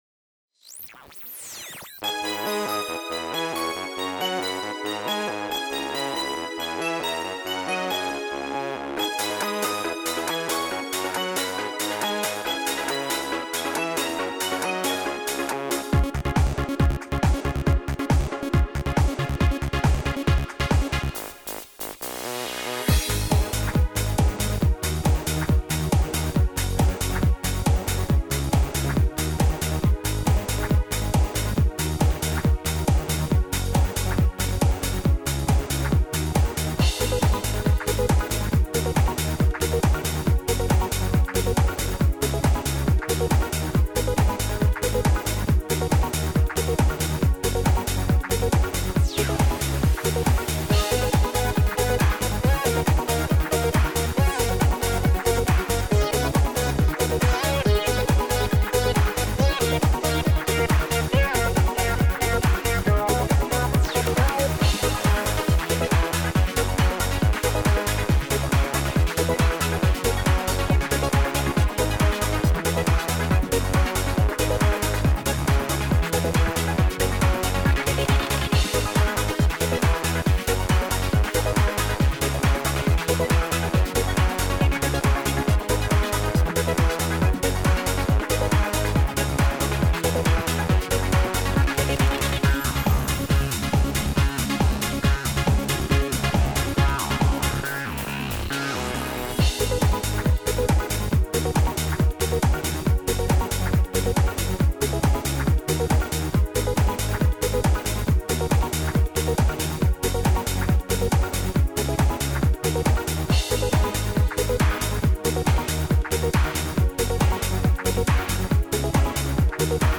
минусовка версия 93642